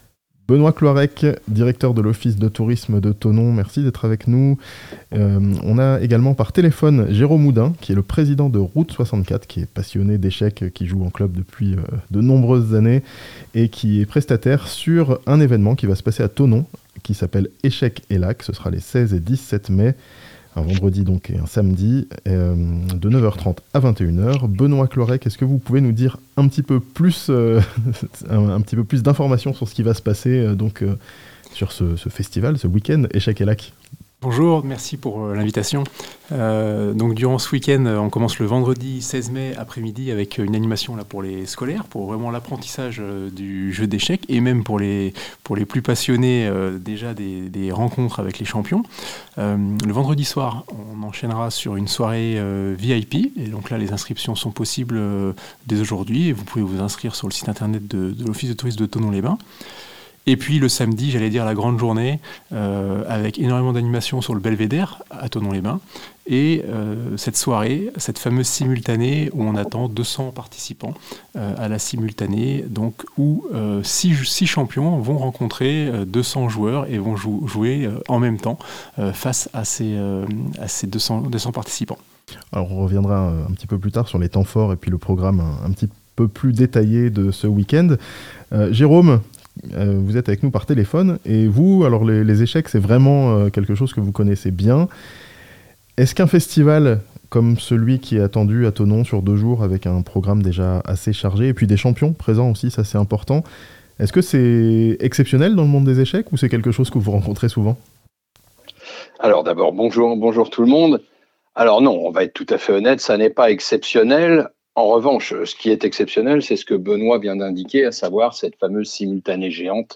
Un weekend dédié aux échecs, à Thonon (interview)